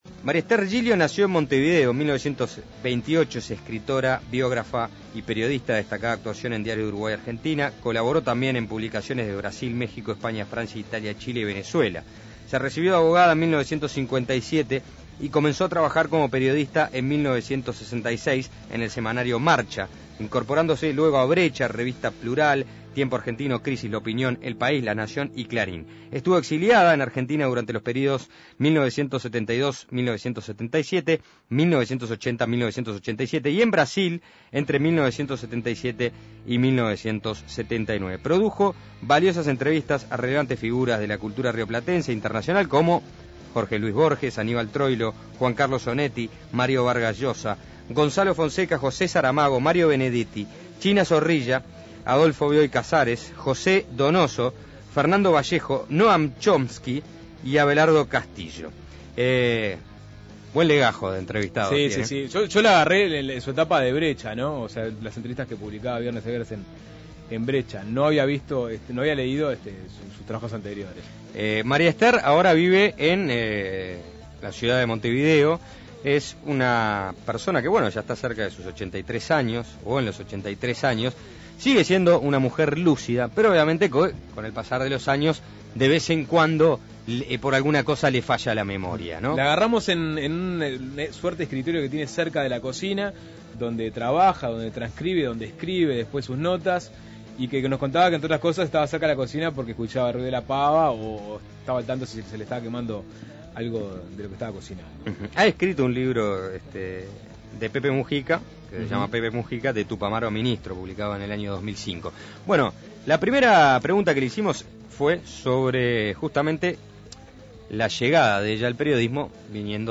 Entrevista a Maria Esther Giglio, maestra de periodismo